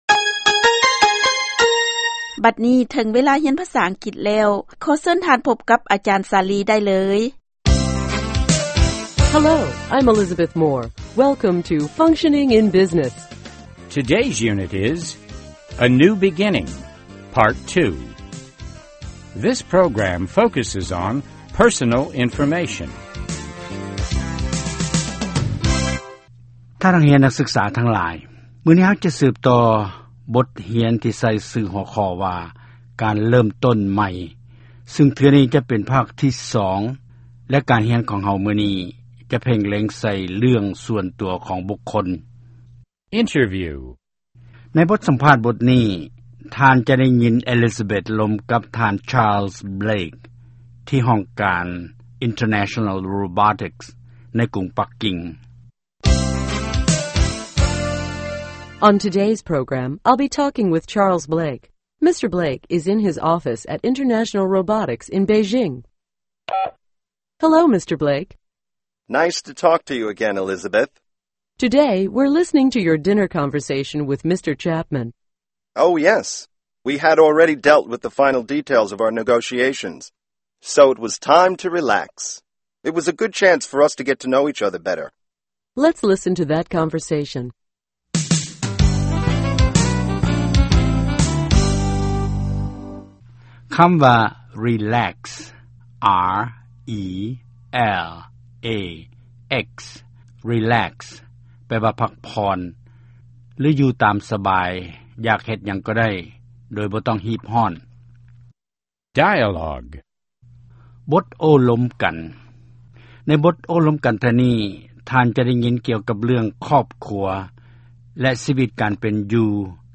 ຟັງບົດຮຽນພາສາອັງກິດ